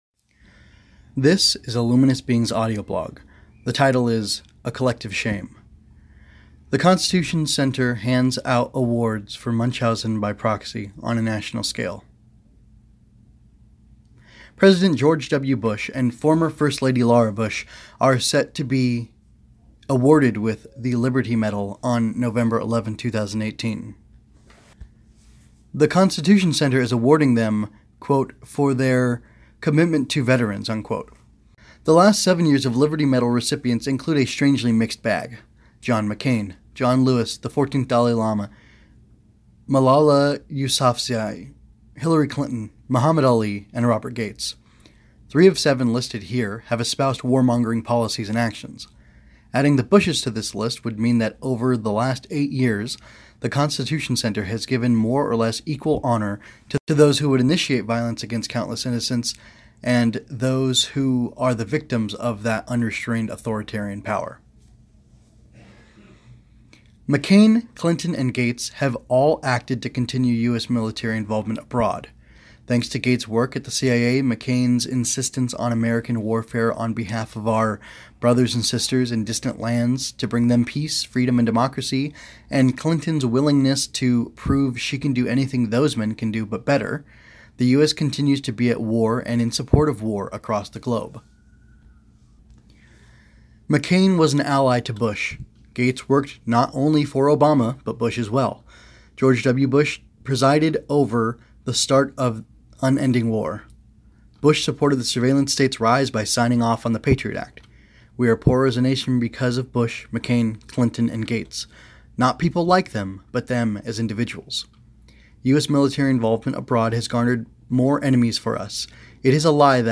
Audioblog version of text below, length 5:32